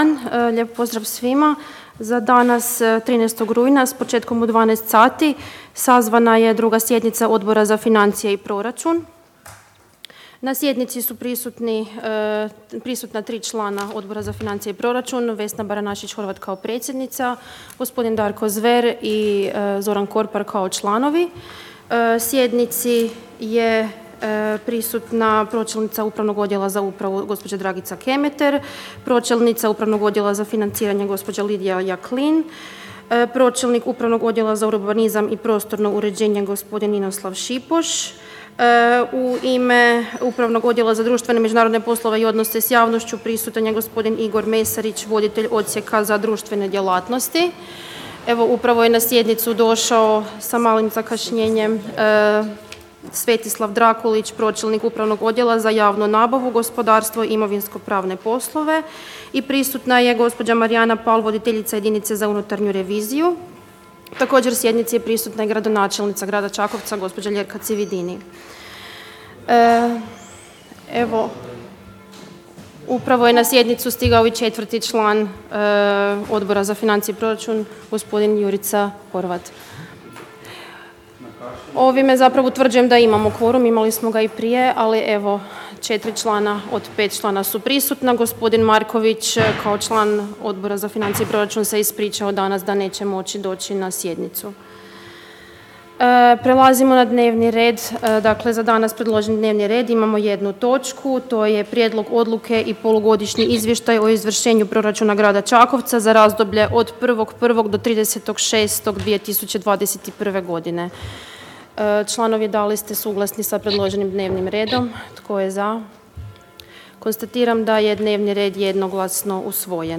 Obavještavam Vas da će se 2. sjednica Odbora za financije i proračun Gradskog vijeća Grada Čakovca održati dana 13. rujna 2021. (ponedjeljak), u 12:00 sati, u gradskoj vijećnici Grada Čakovca.